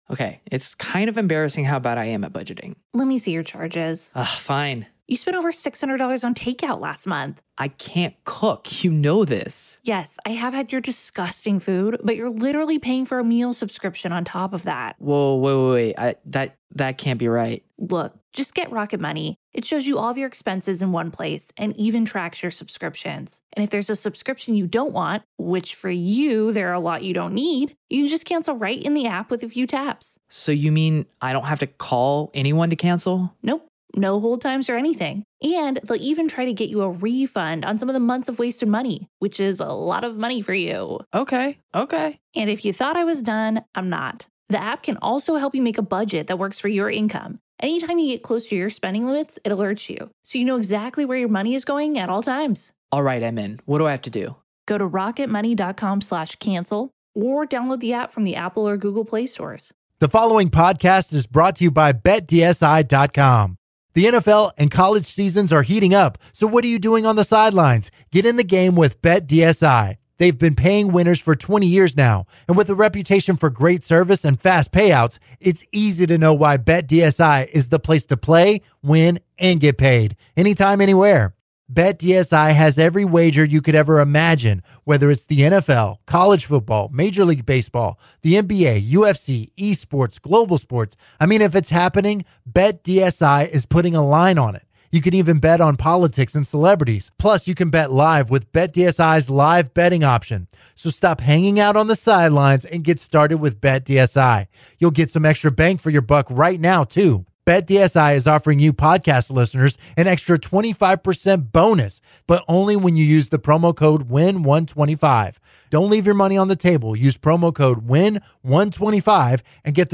They take calls from listeners on the greatest Houston athletes based on radio interviews.